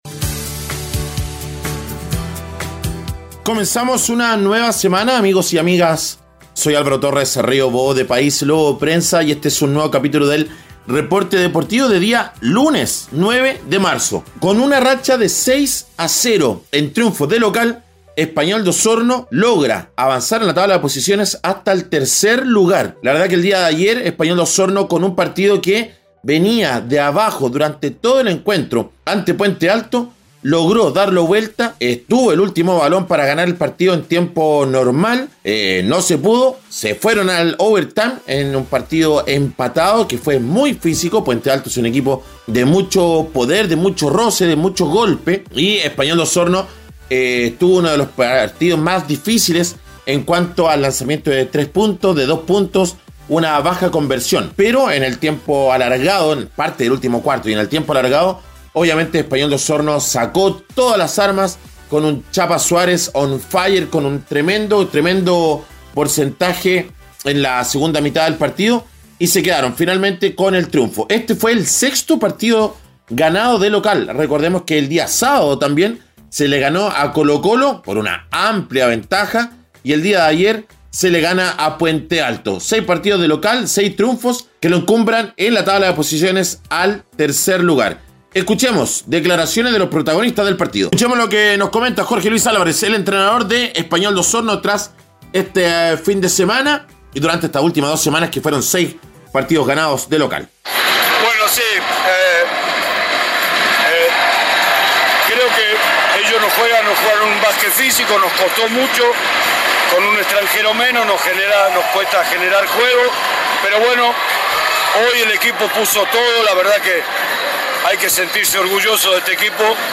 Reporte Deportivo